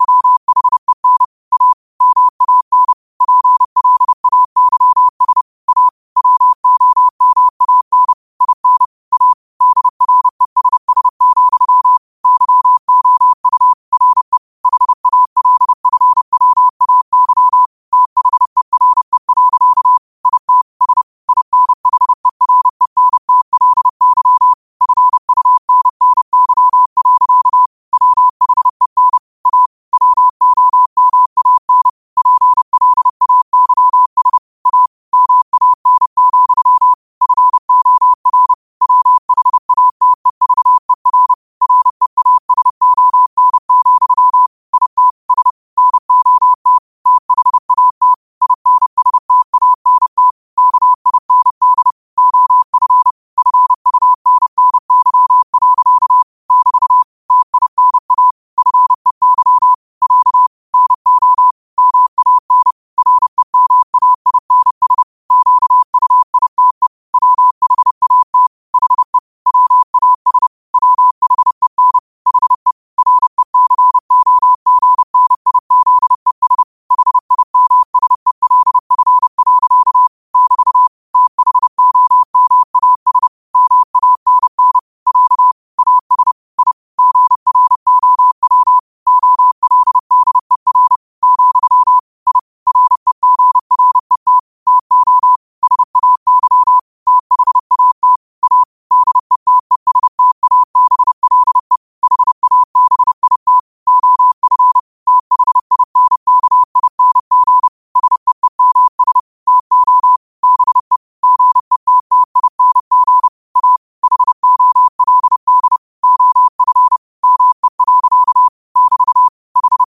New quotes every day in morse code at 30 Words per minute.